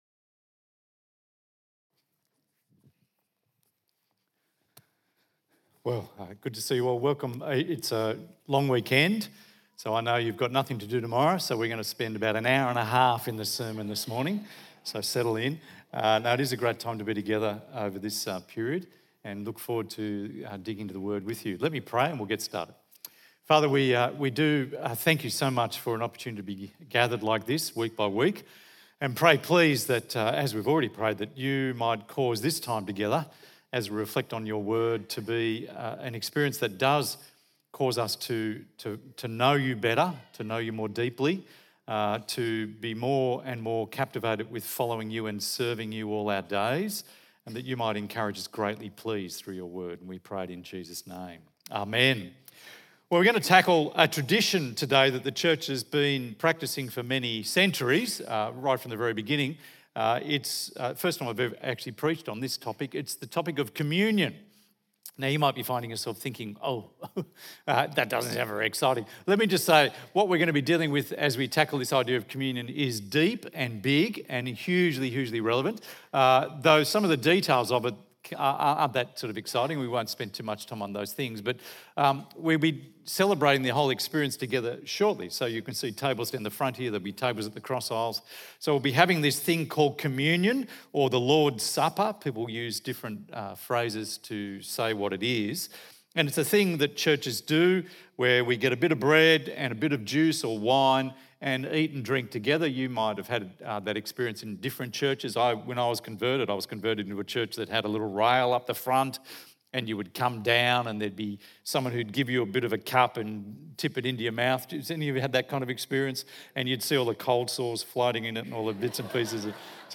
The New Covenant ~ EV Church Sermons Podcast